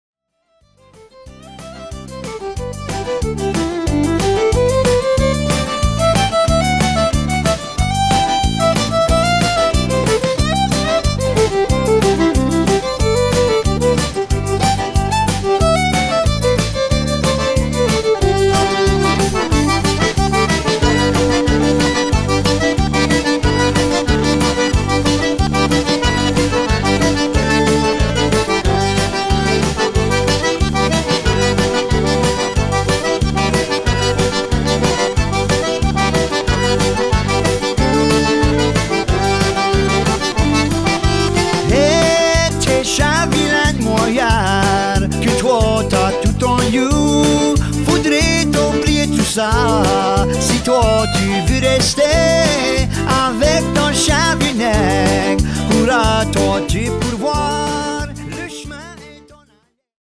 accordions and vocals and acoustic guitar
bass
petite fer